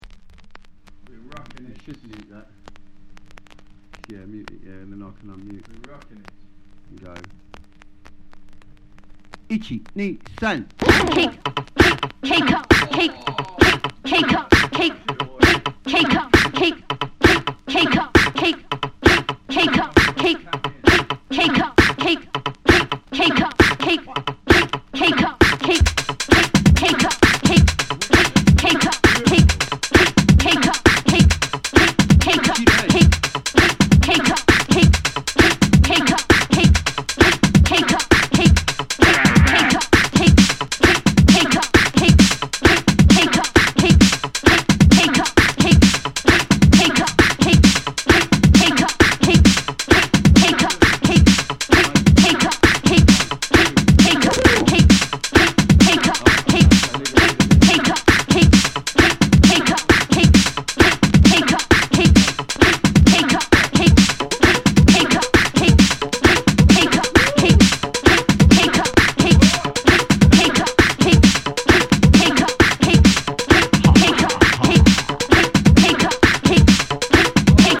ディープテクノミニマル
ヴォイスサンプルの入る